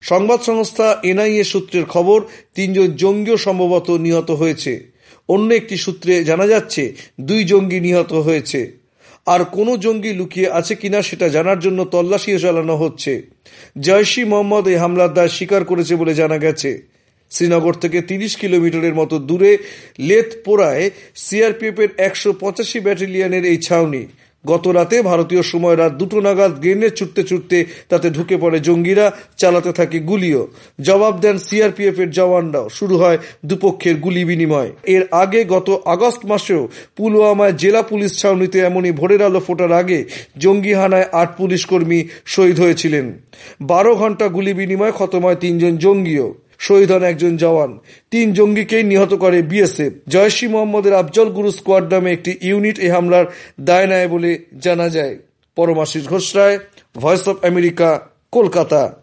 কলকাতা থেকে